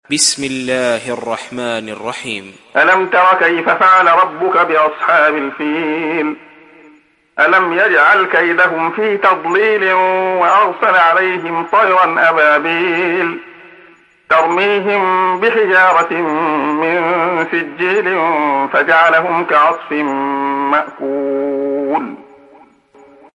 تحميل سورة الفيل mp3 بصوت عبد الله خياط برواية حفص عن عاصم, تحميل استماع القرآن الكريم على الجوال mp3 كاملا بروابط مباشرة وسريعة